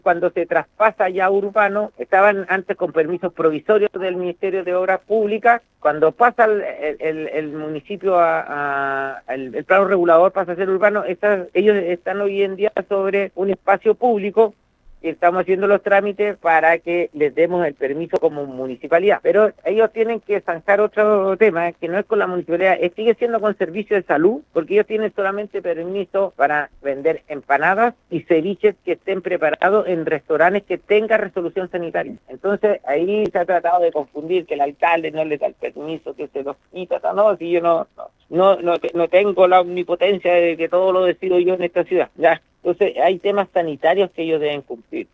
El alcalde Mauricio Soria, en conversación con Radio Paulina, explicó que la situación se arrastra desde hace años, pero que el cambio en la clasificación del terreno agravó el escenario: